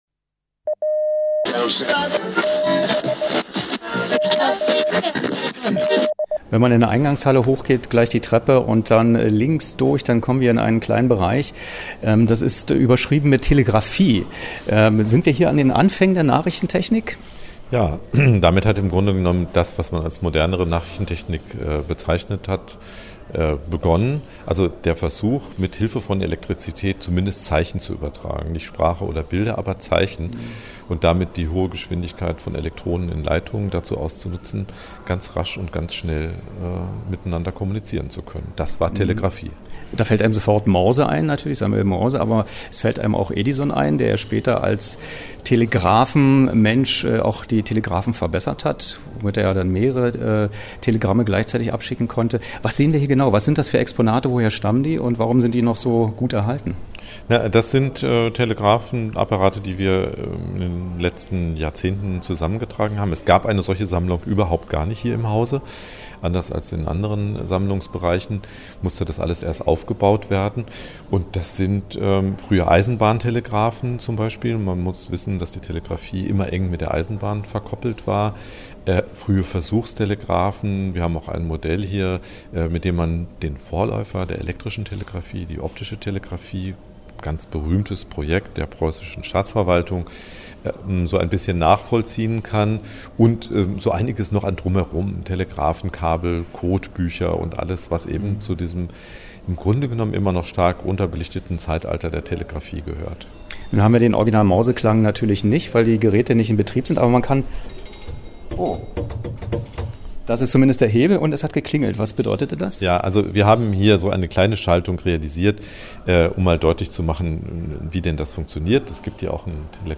Rundgang
Was: Rundgang durch die Abteilung Nachrichtentechnik